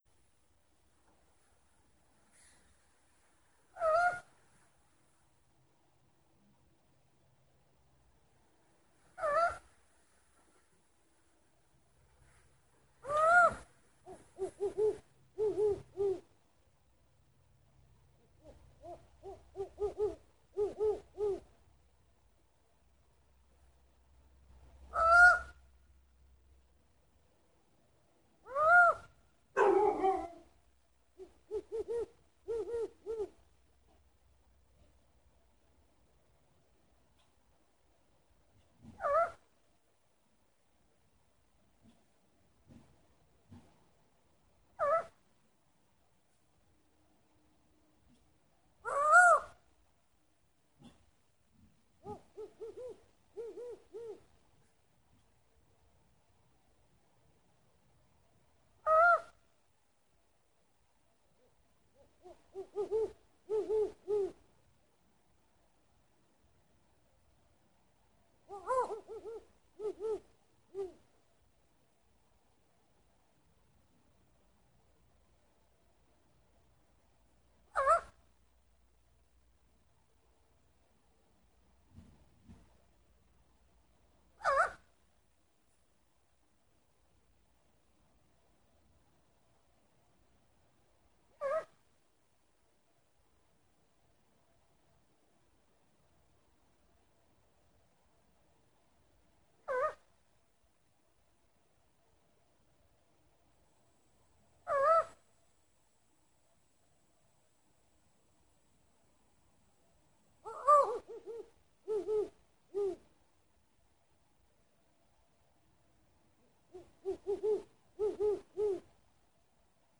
owls